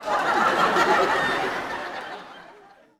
Audience Laughing-03.wav